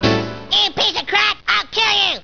sp_chord3.wav